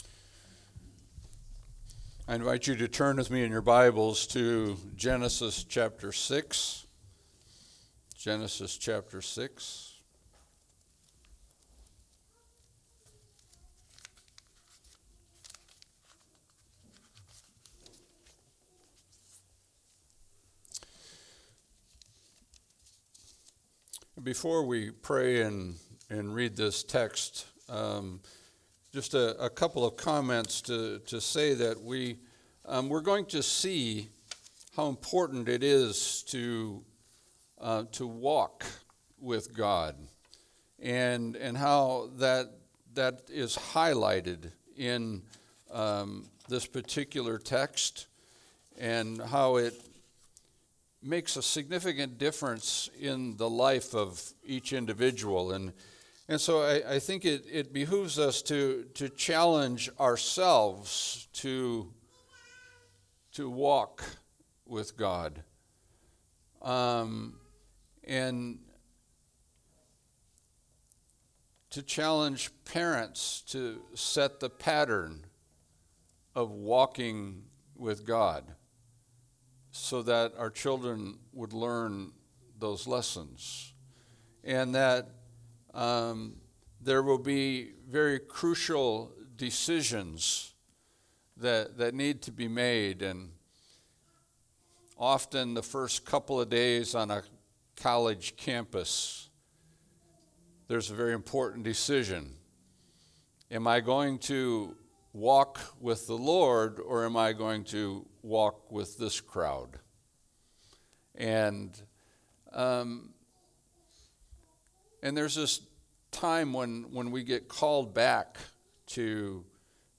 Passage: Genesis 6:1-22 Service Type: Sunday Service